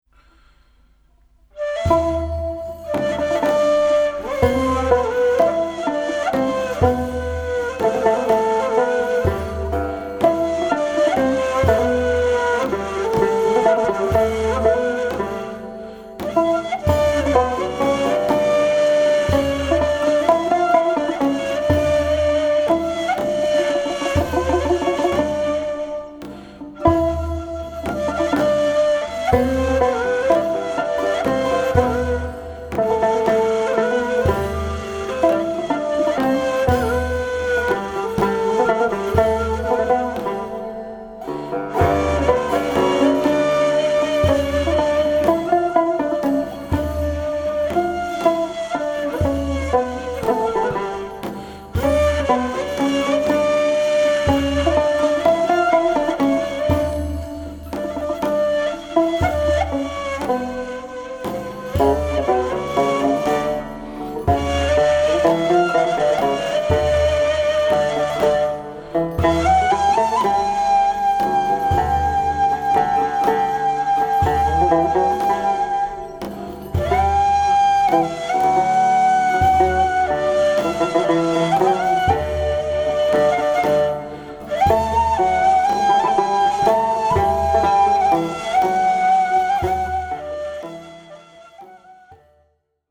Genre: Turkish & Ottoman Classical.
Recorded at Aria Studios, Istanbul
tanbur
ney